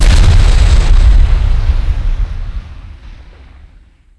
Blast1.wav